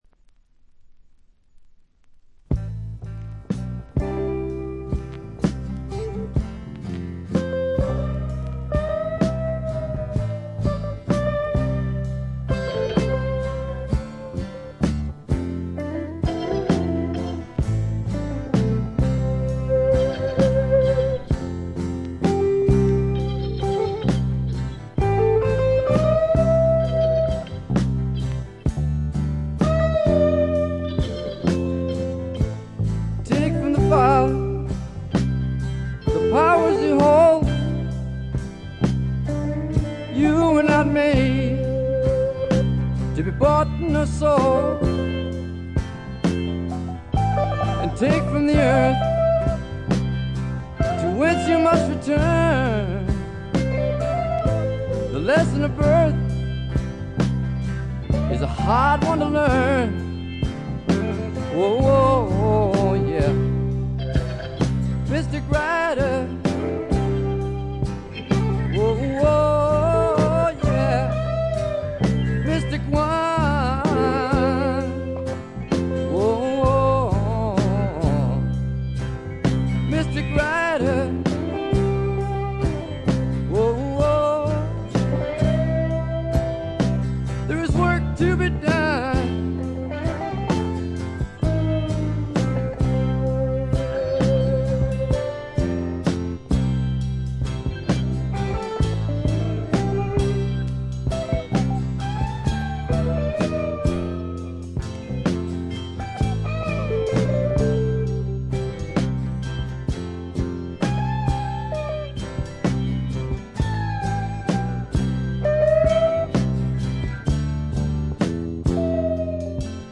中身はヴォーカルも演奏も生々しくラフなサウンドがみっちり詰まっている充実作で、名盤と呼んでよいでしょう。
試聴曲は現品からの取り込み音源です。